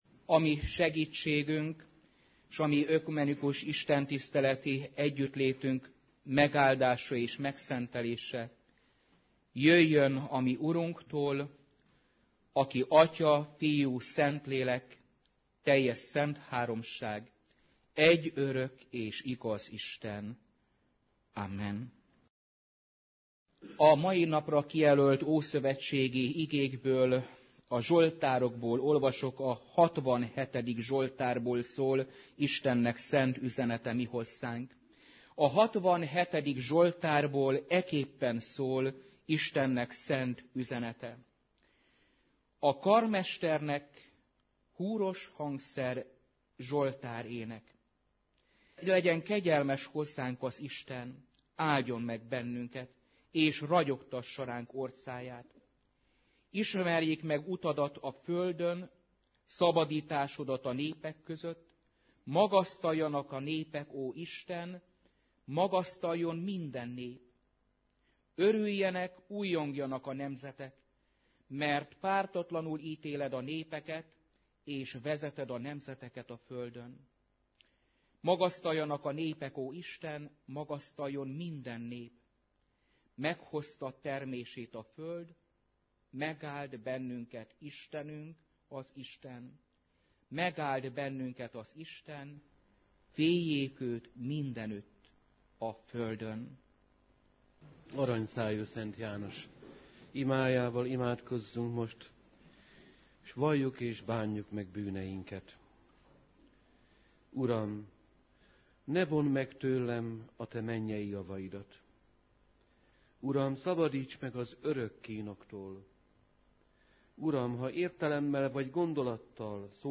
2011 január 21, péntek délután, ökumenikus imahét